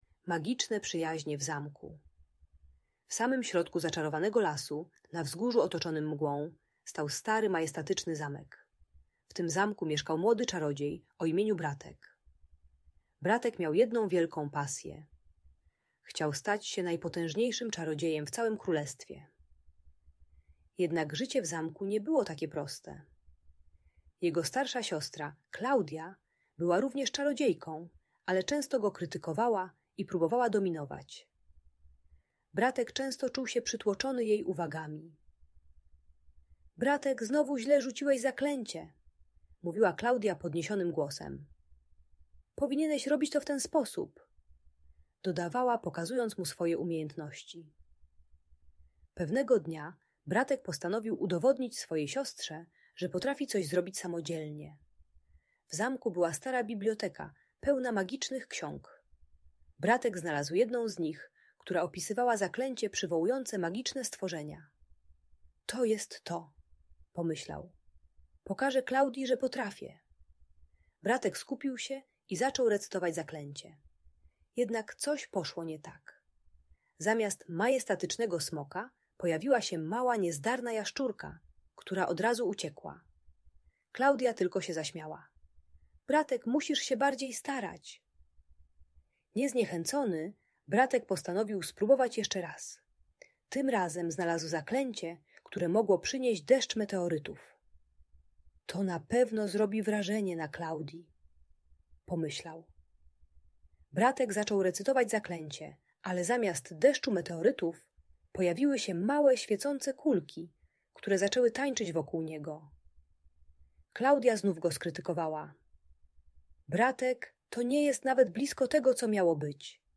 Magiczne Przyjaźnie - Audiobajka